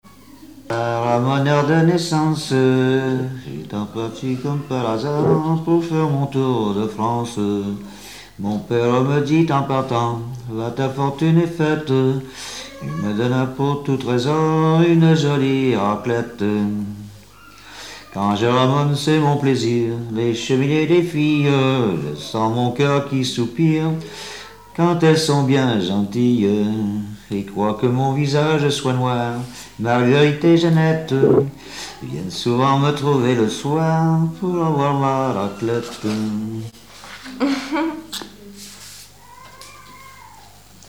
Mémoires et Patrimoines vivants - RaddO est une base de données d'archives iconographiques et sonores.
Genre strophique
Répertoire de chansons populaires et traditionnelles
Pièce musicale inédite